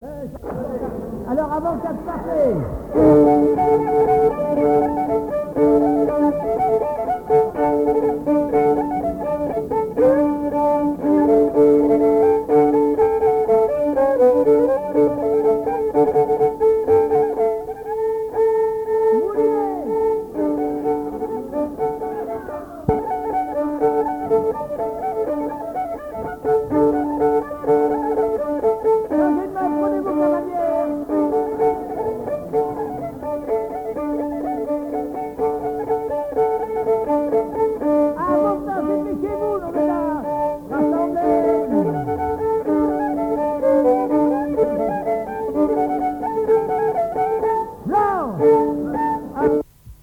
danse : quadrille : moulinet
Pièce musicale inédite